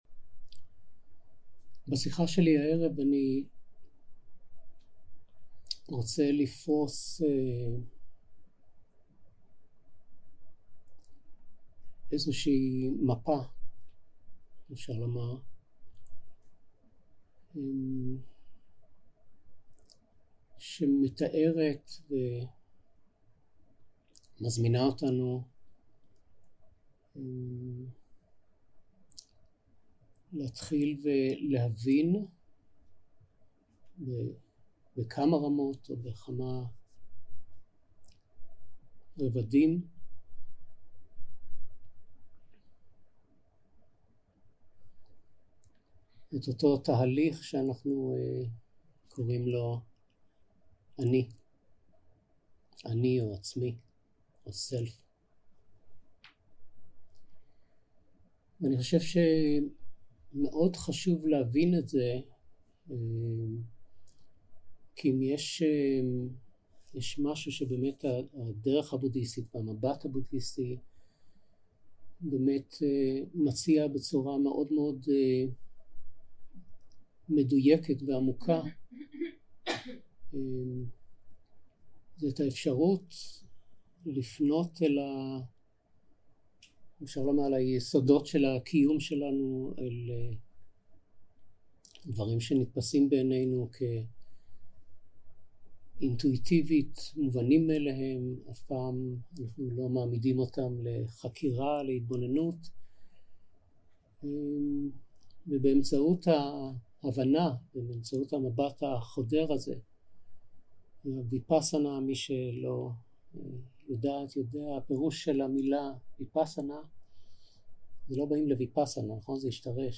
יום 3 - 6 - ערב - שיחת דהרמה To set self not self מפה להבנת תהליך ה selfing
יום 3 - 6 - ערב - שיחת דהרמה To set self not self מפה להבנת תהליך ה selfing 0:00 0:00 סוג ההקלטה: Dharma type: Dharma Talks שפת ההקלטה: Dharma talk language: Hebrew